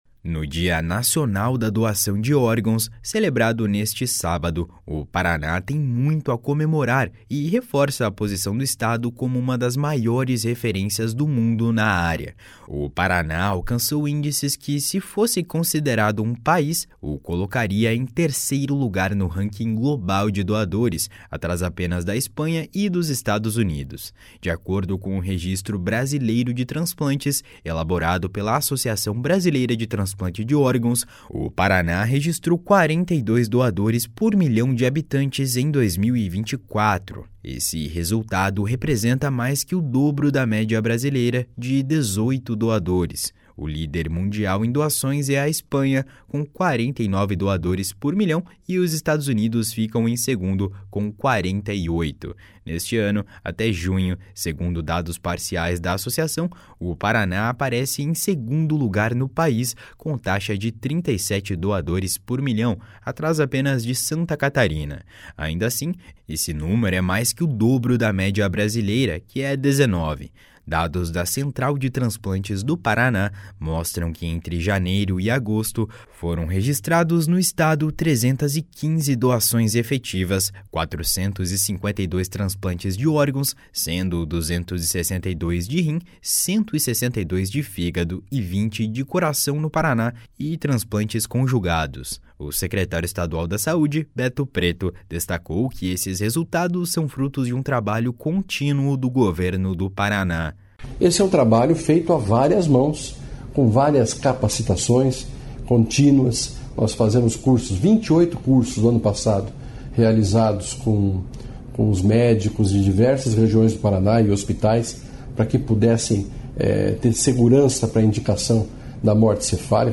O secretário estadual da Saúde, Beto Preto, destacou que esses resultados são fruto de um trabalho contínuo do Governo do Paraná. // SONORA BETO PRETO //